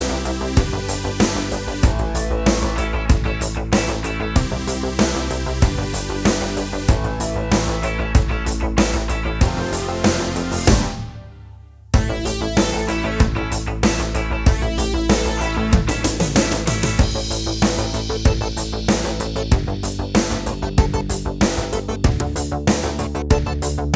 Minus Guitars Except Rhythm Pop (1980s) 4:02 Buy £1.50